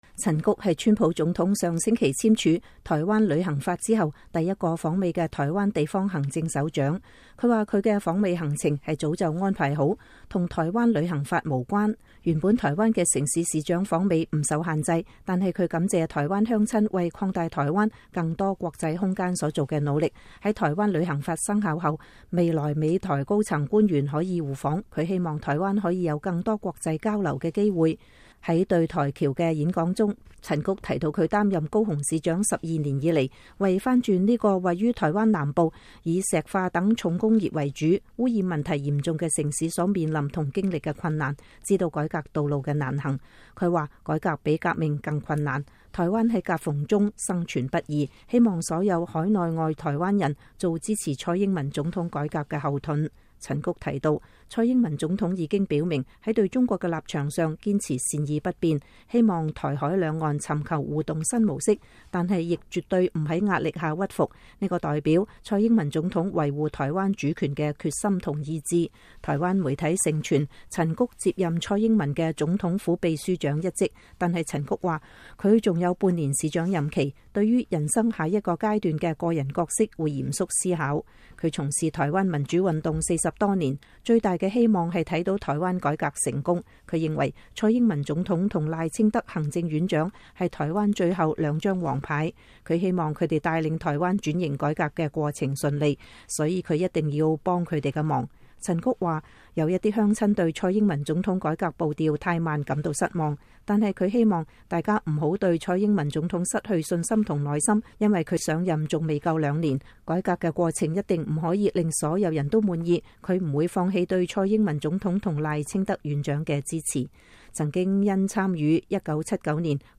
正在美國訪問的台灣高雄市長陳菊星期一在馬里蘭州一個演講中呼籲海外台僑做蔡英文總統改革的後盾，支持她不在壓力下屈服和對台灣的承諾。